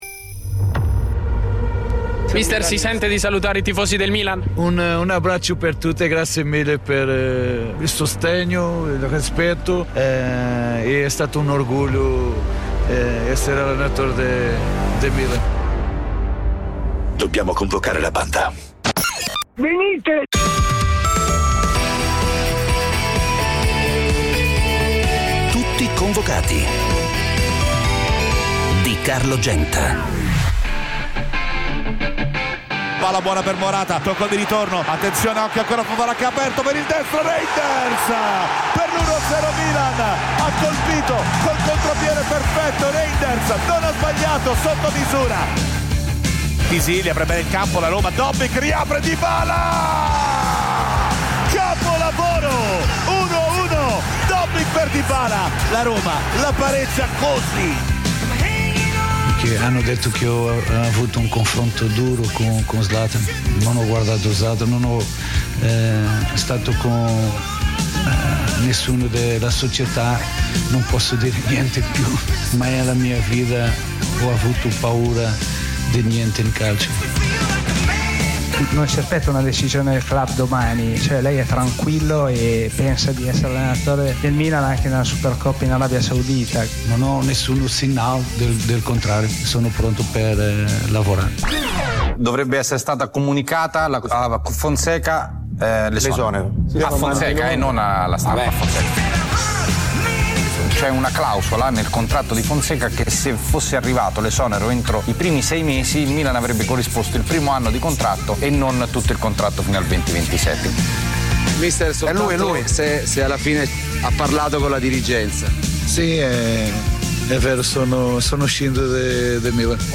Le voci e i suoni del calcio. Una lettura ironica e coinvolgente degli avvenimenti dell'attualità sportiva, senza fanatismi e senza tecnicismi. Commenti, interviste e soprattutto il dibattito con gli ascoltatori, che sono Tutti convocati.
Con imitazioni, tic, smorfie, scherzi da spogliatoio e ironia irriverente. L'unico programma in diretta sul calcio a partite appena finite, in cui da casa o dallo stadio gli ascoltatori possono dire la loro perché sono sempre convocati.